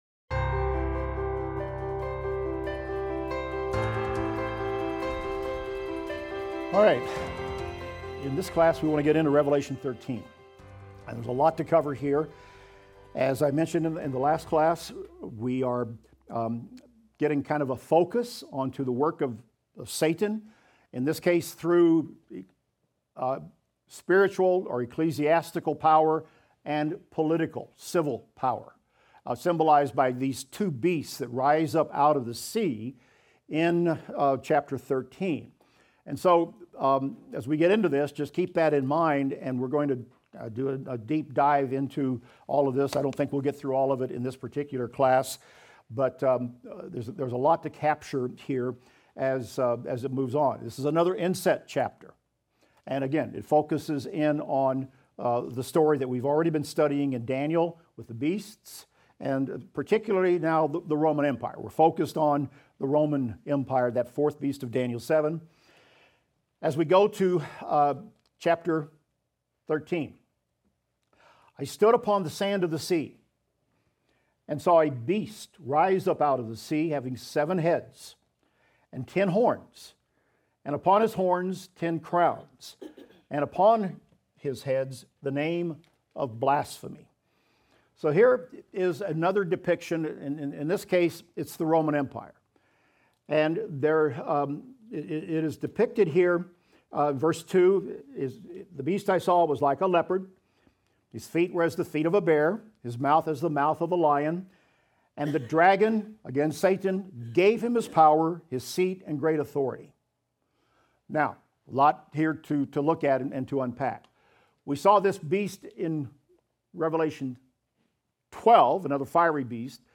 Revelation - Lecture 42 - audio.mp3